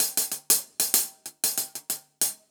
Index of /musicradar/ultimate-hihat-samples/95bpm
UHH_AcoustiHatC_95-02.wav